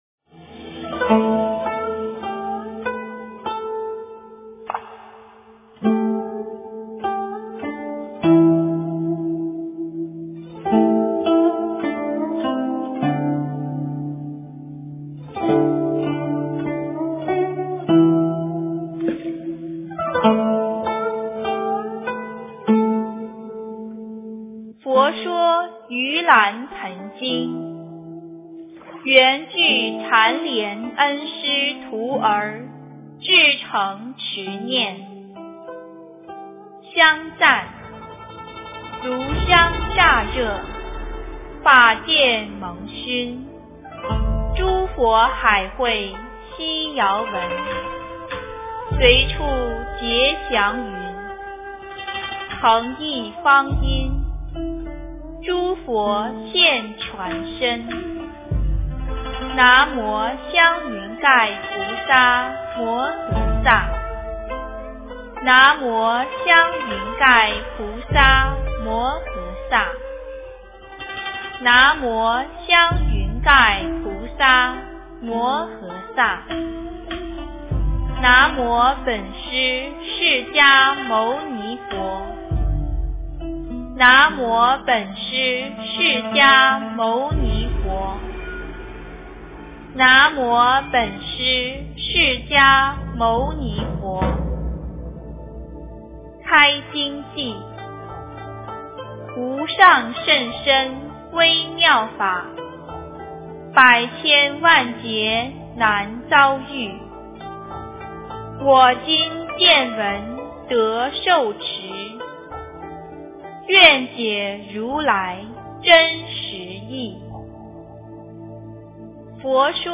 佛说盂兰盆经 - 诵经 - 云佛论坛
佛音 诵经 佛教音乐 返回列表 上一篇： 大般若波罗蜜多经第497卷 下一篇： 大般若波罗蜜多经第498卷 相关文章 冬序--五台山普寿寺梵乐小组 冬序--五台山普寿寺梵乐小组...